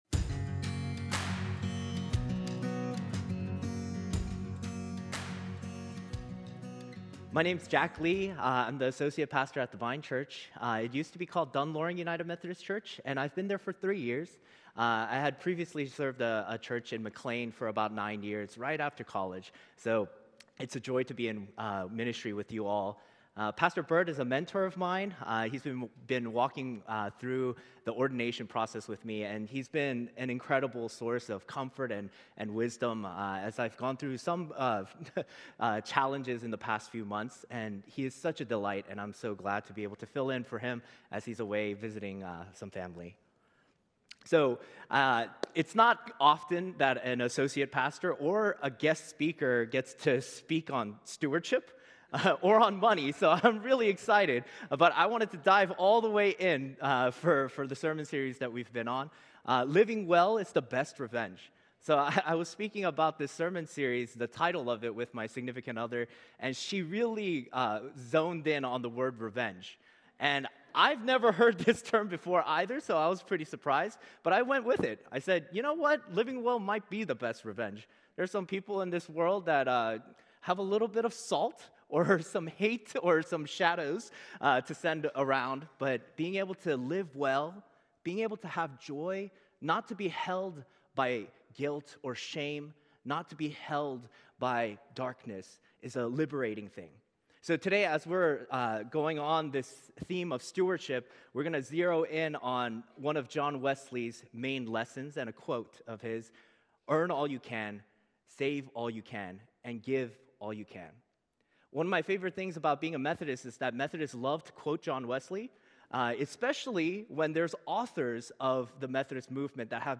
Guest Pastor